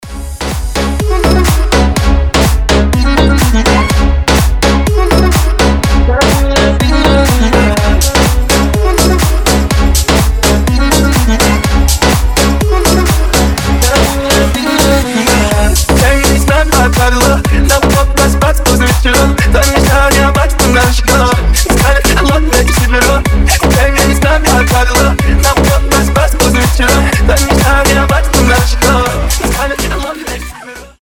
• Качество: 320, Stereo
громкие
мощные
Club House
восточные
энергичные
electro house
ремиксы